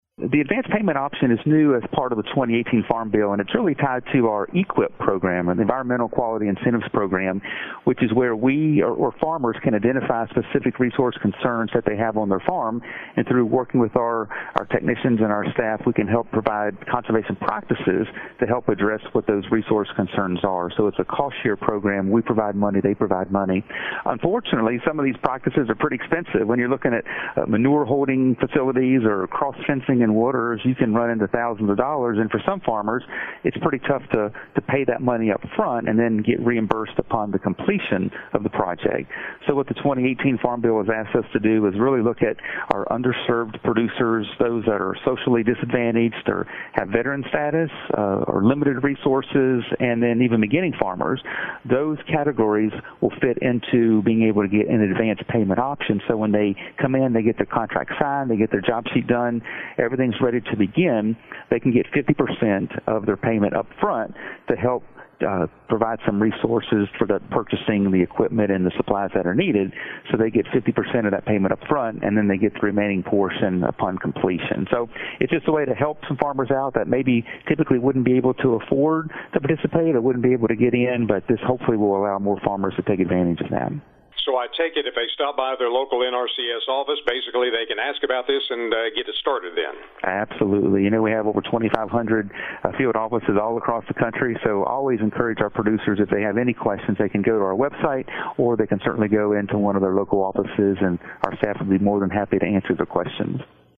NRCS Chief Matthew Lohr explains how this program works.